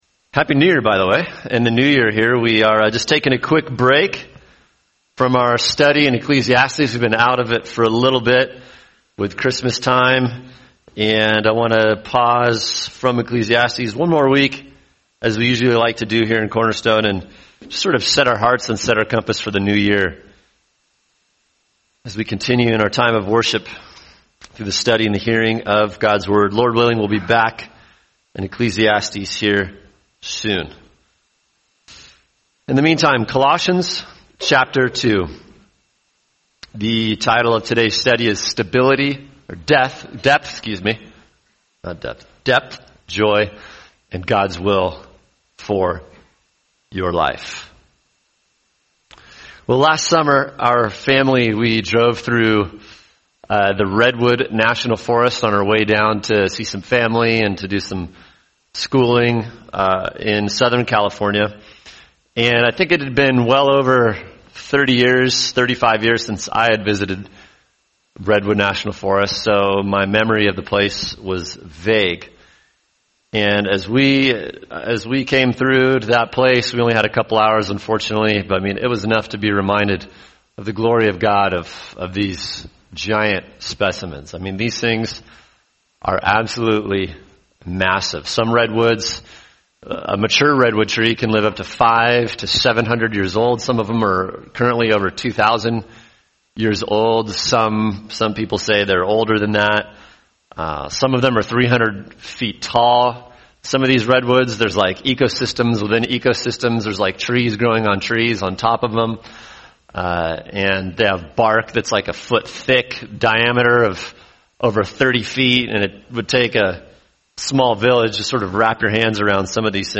[sermon] Colossians 2:6-7 – Depth, Joy, and God’s Will for Your Life | Cornerstone Church - Jackson Hole